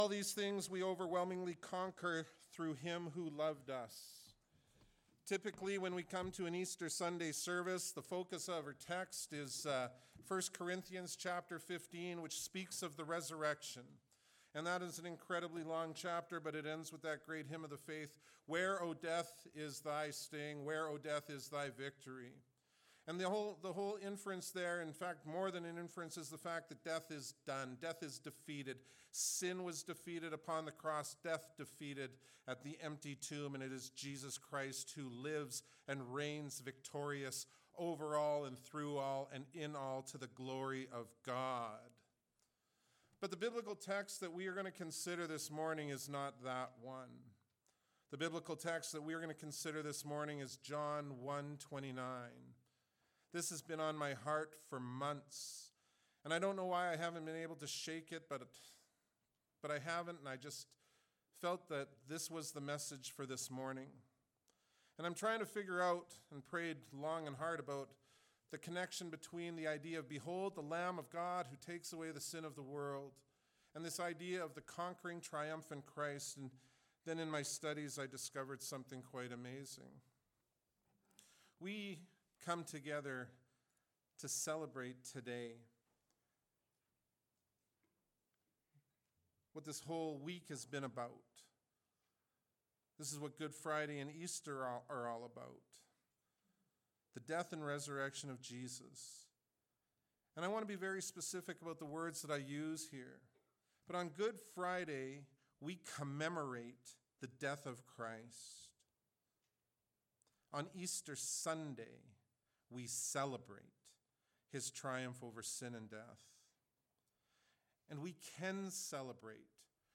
Bible Text: John 1:29-34 | Preacher